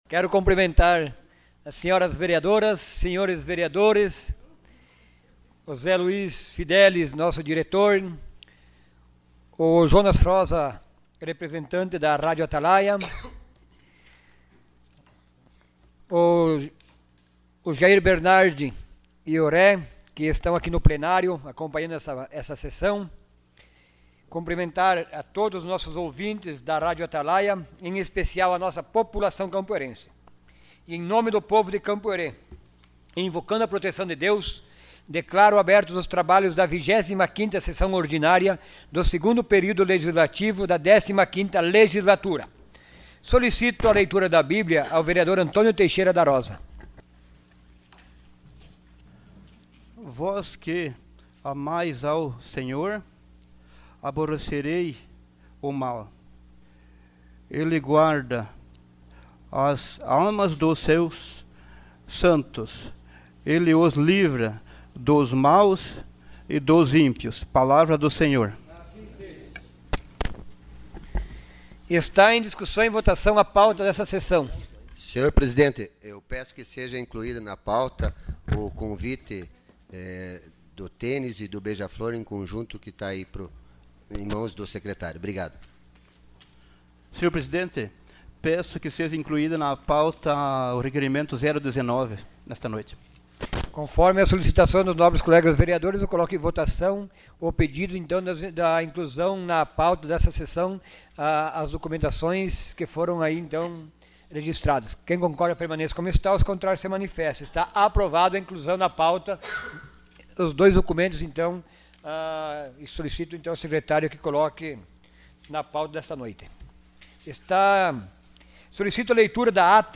Sessão Ordinária dia 07 de junho de 2018.